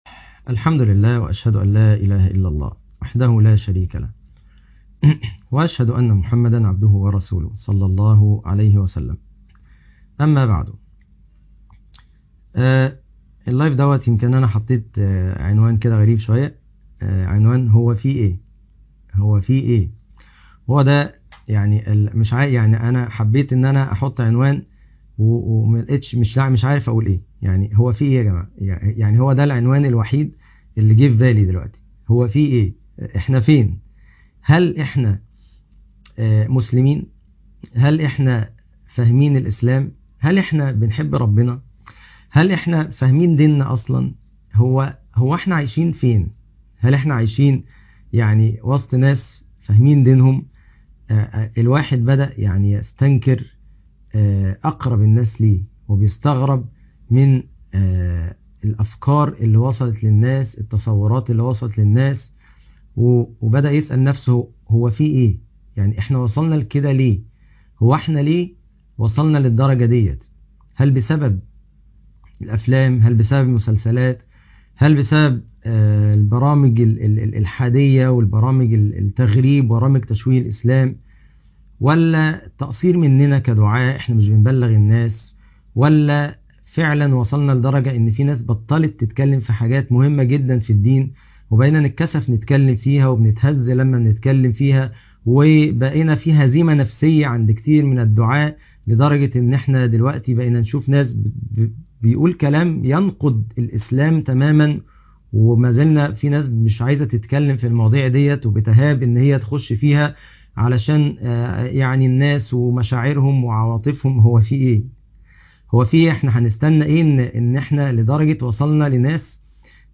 الترحم على الكافر و شوية حاجات تانية مهمة - لايف خطير - قسم المنوعات